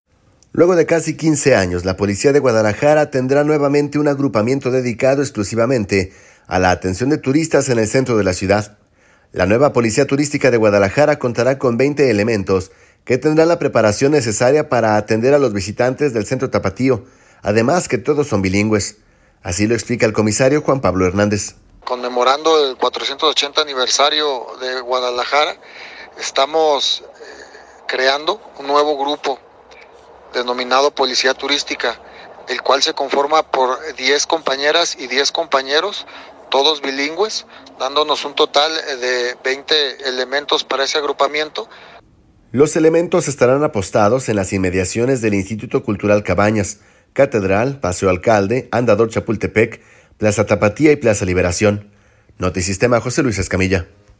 La nueva policía turística de Guadalajara contará con 20 elementos que tendrán la preparación necesaria para atender a los visitantes del centro tapatío, además que todos son bilingües. Así lo explica el comisario Juan Pablo Hernández.